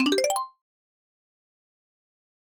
dm_sent.ogg